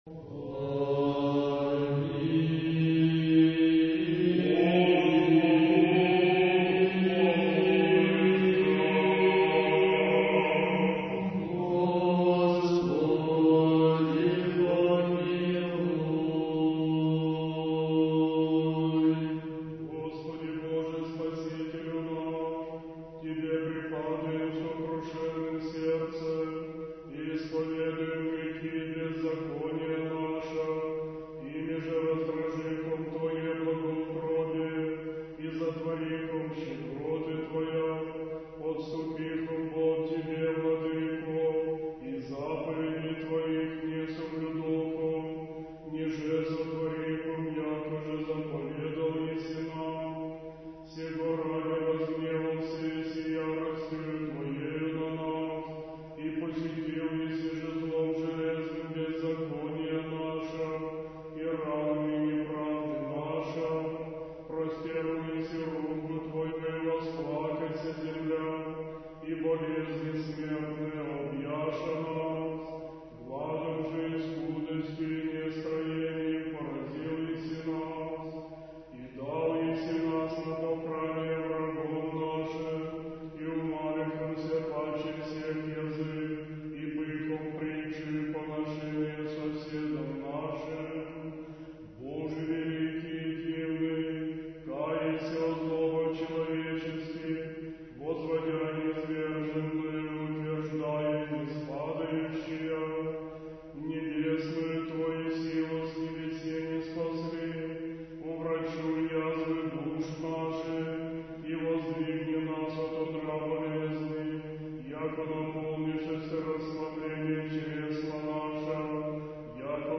Духовная музыка / Русская